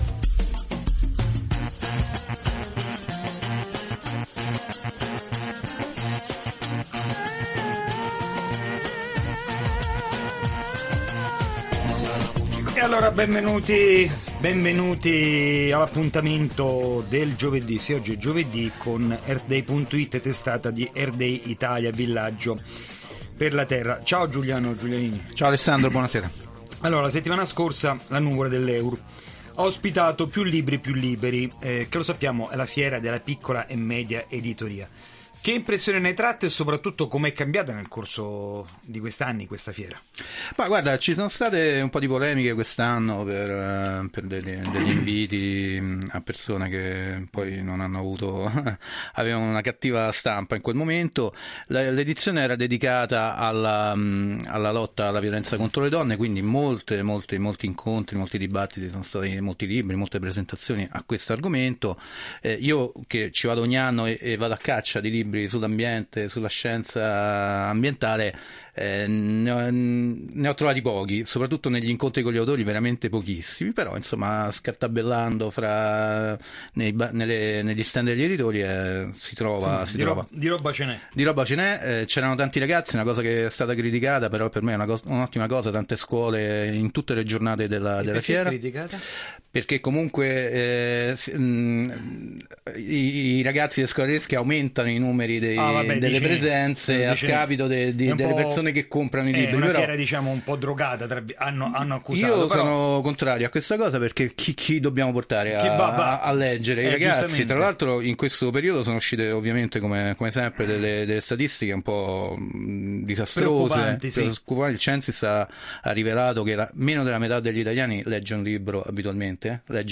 Come ogni anno abbiamo girovagato tra stand e presentazioni, per raccogliere voci e verificare quanto, e in che modo, gli argomenti dell’ambiente siano proposti al lettore italiano.
Abbiamo comunque avuto modo di registrare le voci di chi ha portato in fiera autori ed opere di divulgazione scientifica.
Agli ascoltatori abbiamo proposto tre interviste.